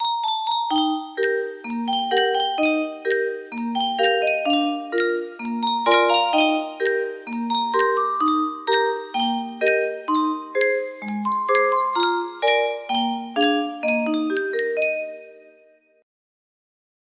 Yunsheng Custom Tune Music Box - Sipping Cider Through a Straw music box melody
Full range 60